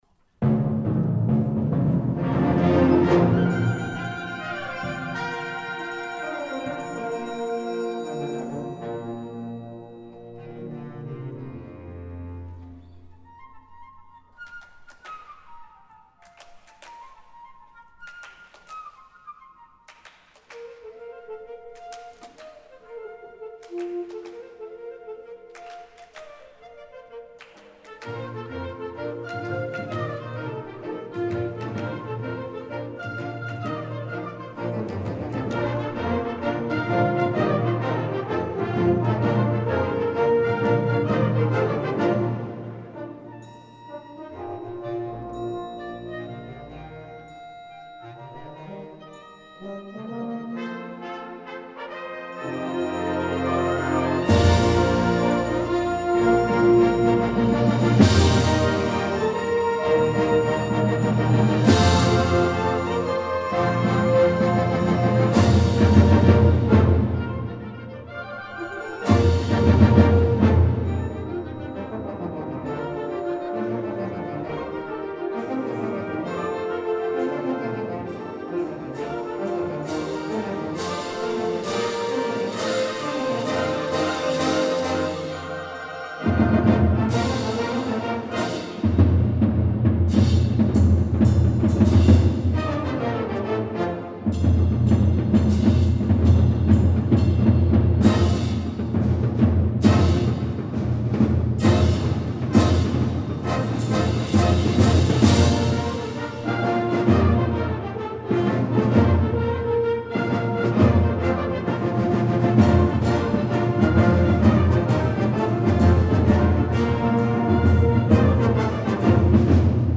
West Monroe High School Band 2018-2019
Spring Concert